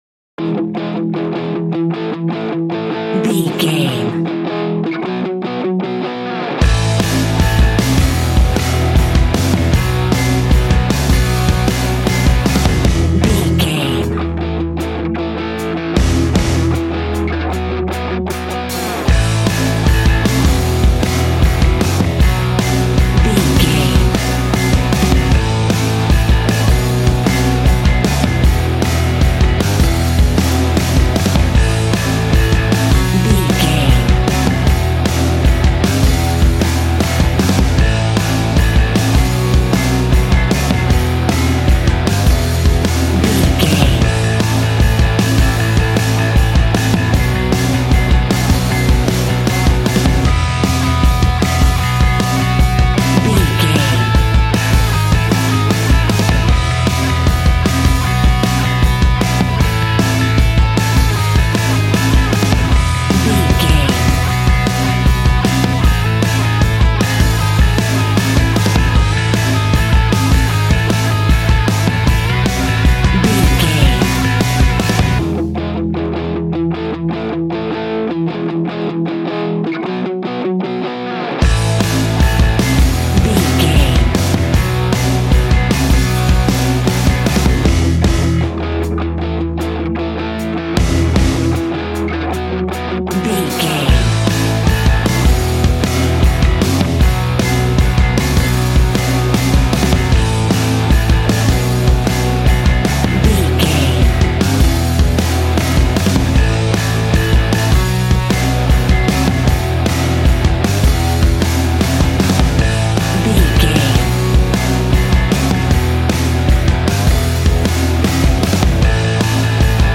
Fast paced
Ionian/Major
groovy
powerful
electric organ
drums
electric guitar
bass guitar